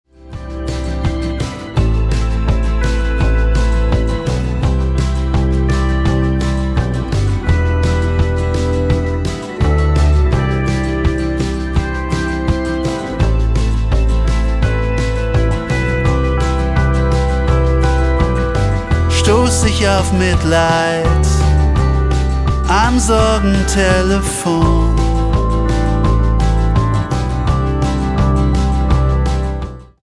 zu Herzen gehende Melancholie